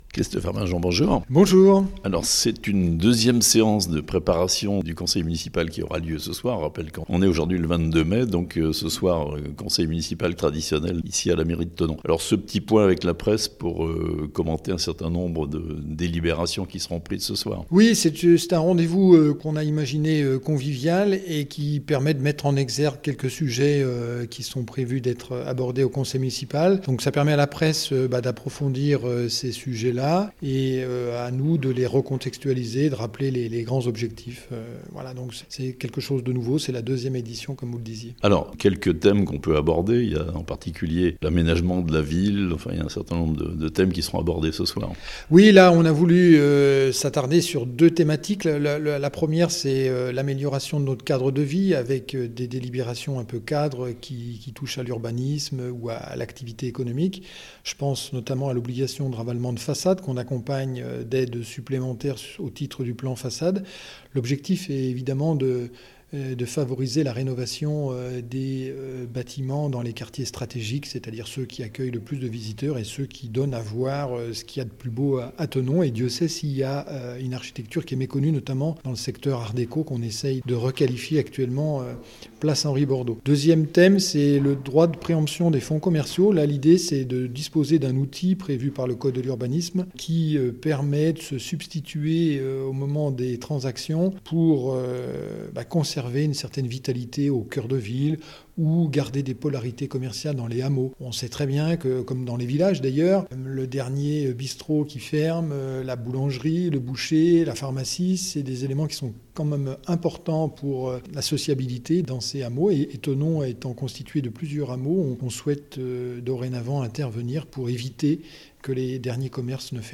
Christophe Arminjon, Maire de Thonon, au micro La Radio Plus